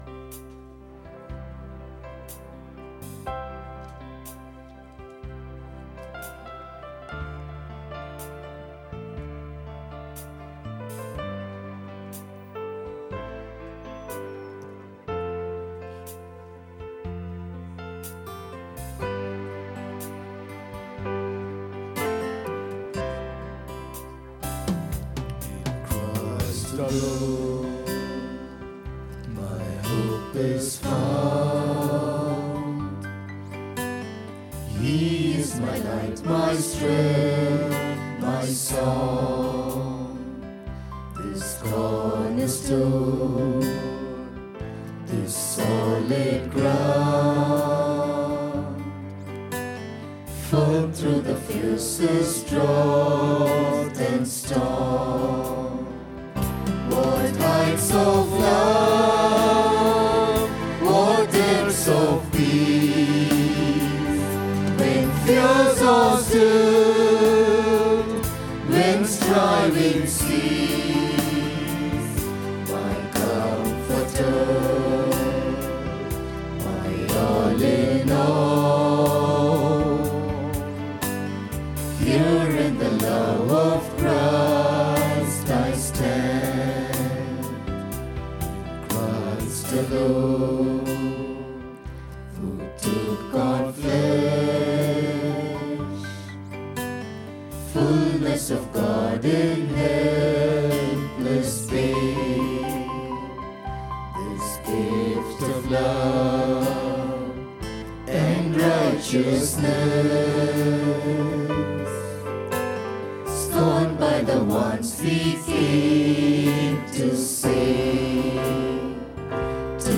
26 June 2022 Sunday Morning Service – Christ King Faith Mission